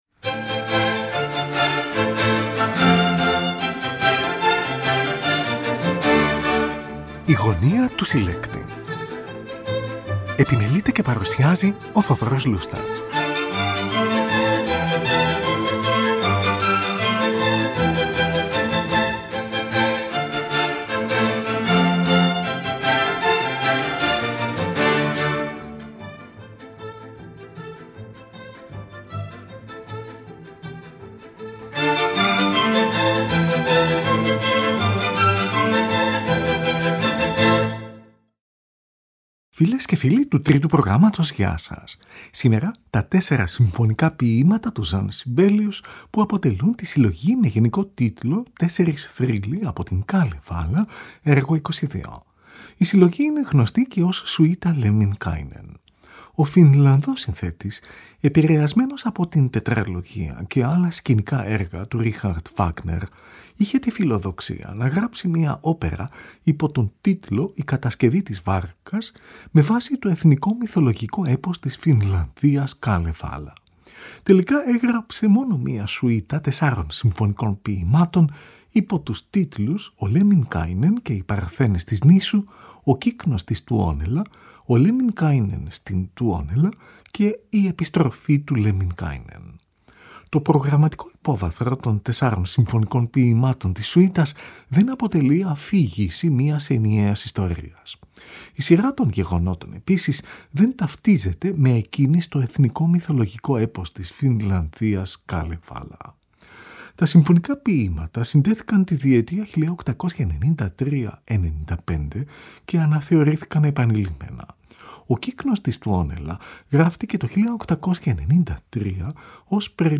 Η συλλογή – που είναι γνωστή και ως «Σουίτα Lemminkäinen» – αποτελείται από τα ακόλουθα συμφωνικά ποιήματα του Jean Sibelius: